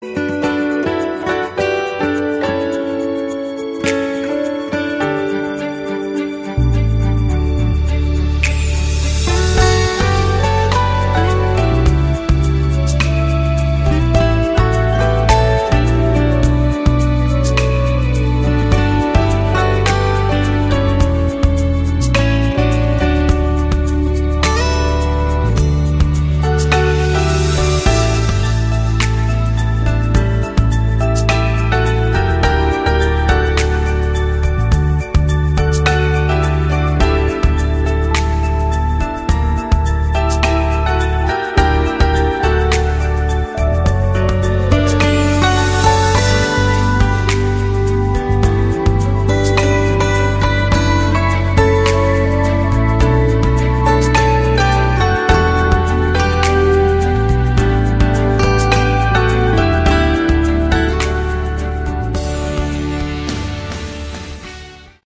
Category: AOR
Guitars
what a glorious atmosphere this track has.